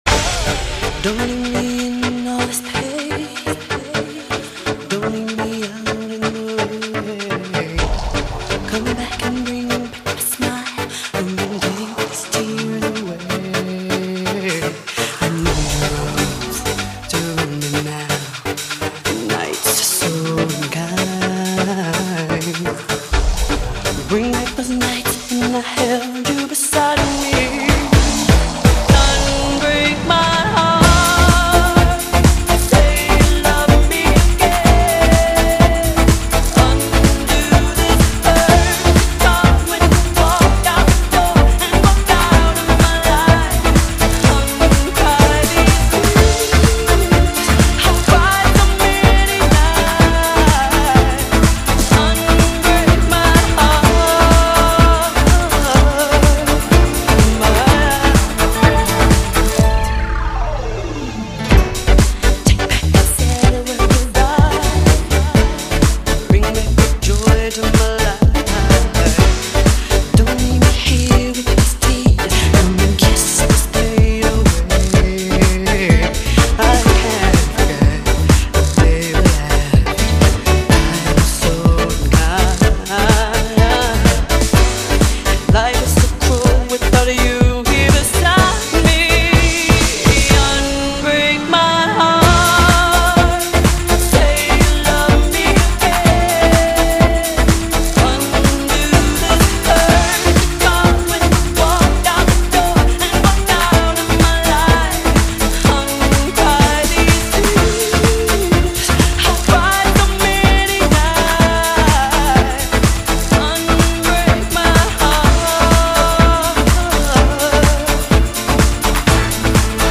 音樂類型：西洋音樂